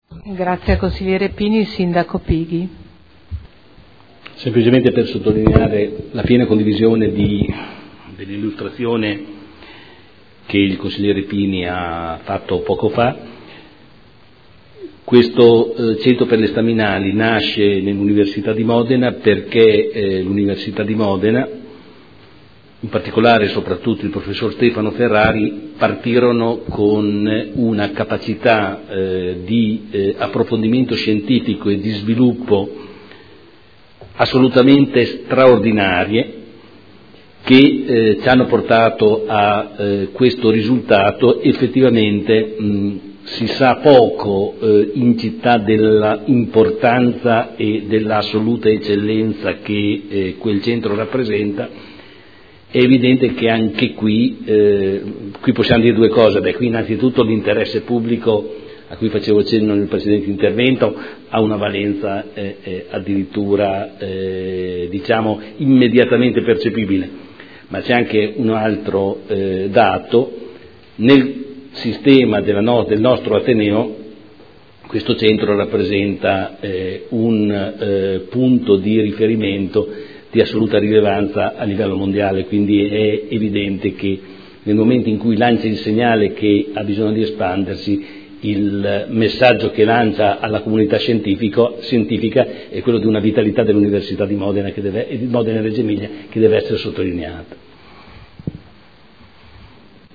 Giorgio Pighi — Sito Audio Consiglio Comunale
Proposta di deliberazione: Proposta di progetto - Ampliamento del Centro Medicina Rigenerativa – Via Gottardi – Z.E. 473 area 01 – Nulla osta in deroga agli strumenti urbanistici comunali – Art. 20 L.R. 15/2013. Dibattito